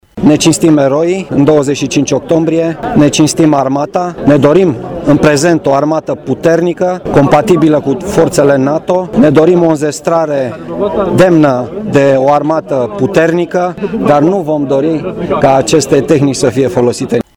Un ceremonial militar şi religios s-a desfăşurat la Cimitirul Eroilor Şprenghi.
Printre oficalităţile prezente s-a aflat şi prefectul Braşovului, Marian Rasaliu: